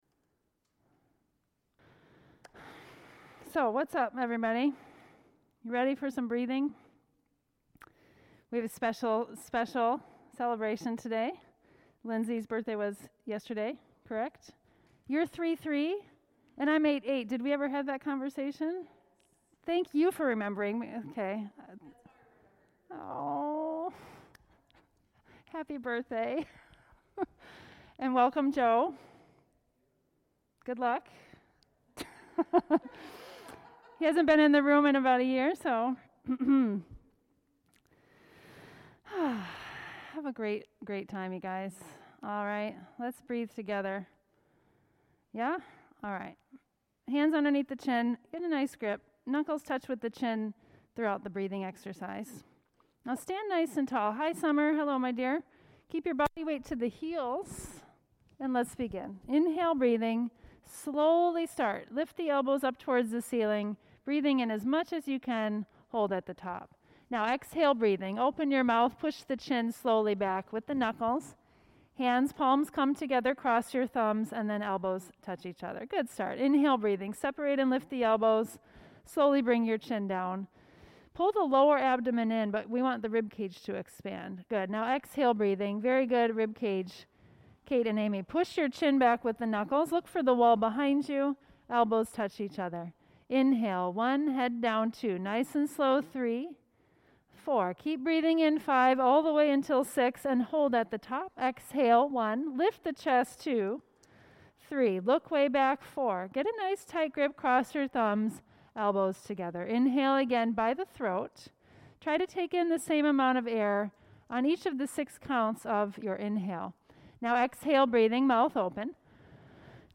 Sit-Up Tutorial